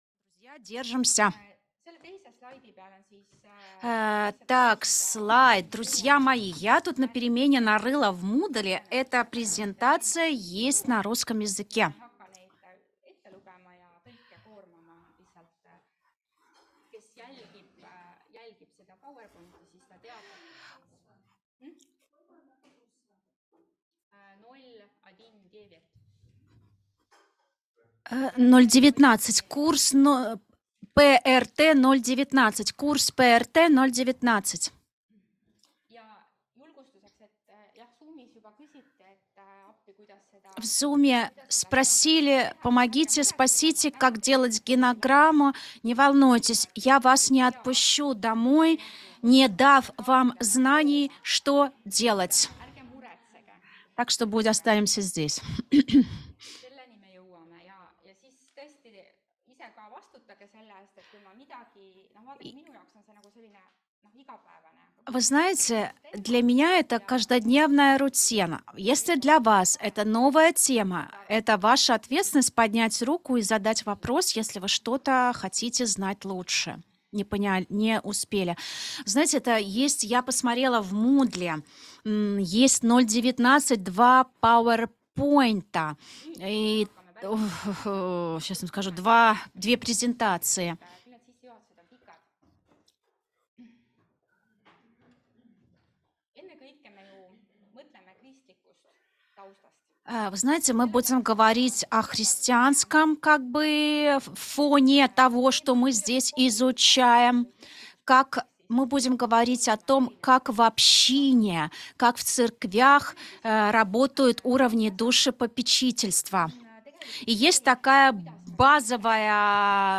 Семейное консультирование. 2. лекция [RU] – EMKTS õppevaramu